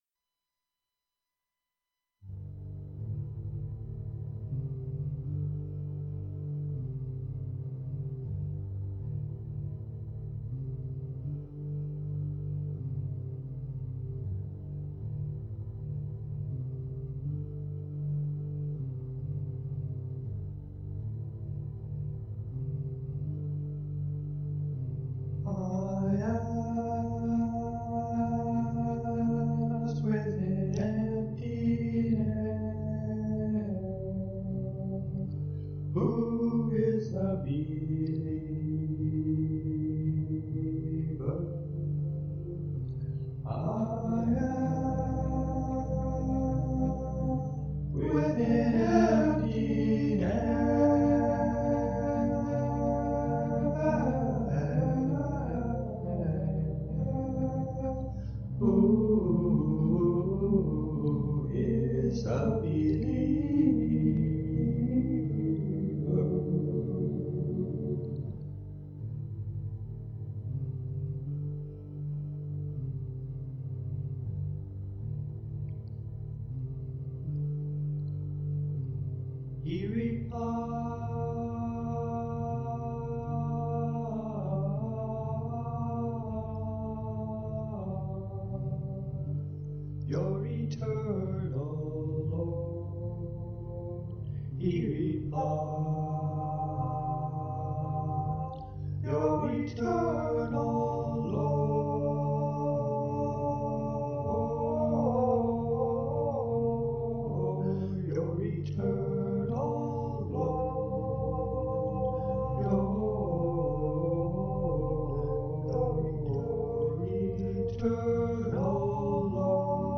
transposed the music down a perfect fifth (from D-minor to G-minor)
singing alto
singing tenor and a little of the alto
We then combined these tracks in order to give a more choir-like effect.
I wanted a soft sound for the instrumental accompaniment in the bass clef. I tried a number of electronically-simulated instrumental sounds and eventually chose a more voice-like sound described as “Choral Oohs.”
We would have liked to have been able to provide a greater range of volume for sound systems with small speakers, but we found that we could not avoid significant distortion when we amplified the recording.